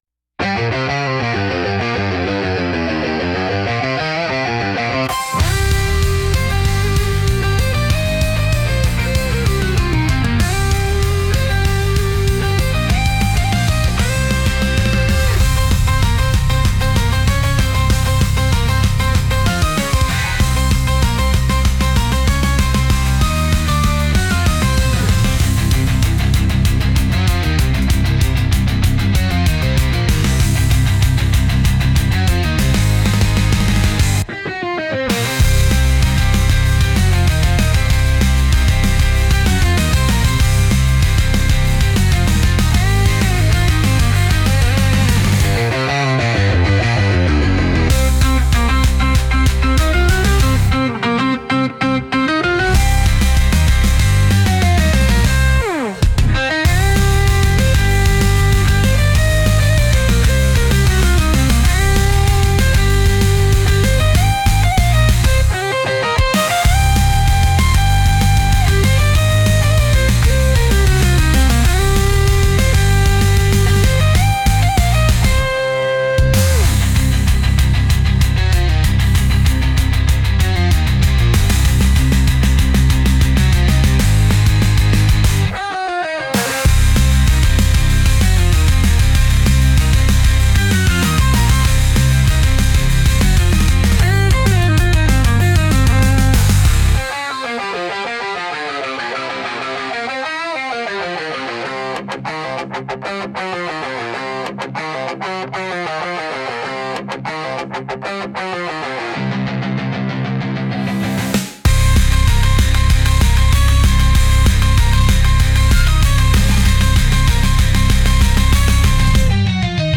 偶然戦闘曲っぽく形になったので嬉しいです。
ジャカジャカ鳴らすのは好きなのです。